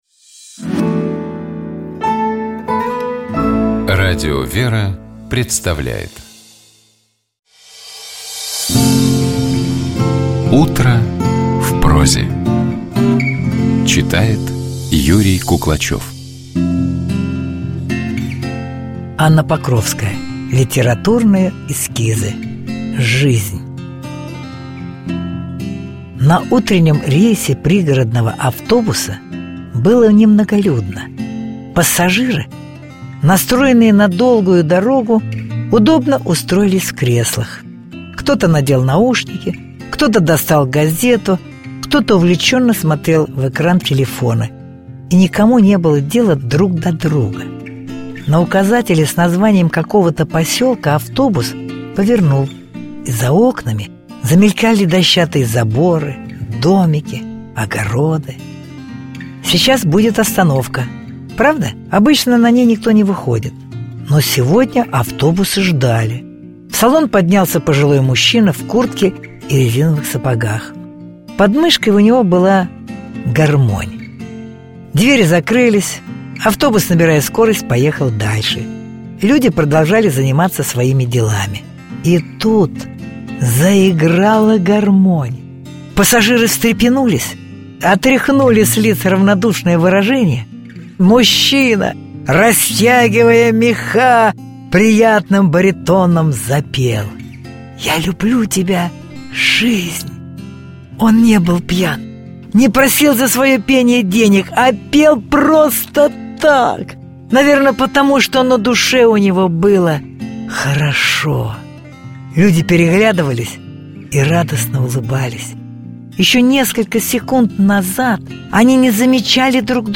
Текст Анны Покровской читает Юрий Куклачев.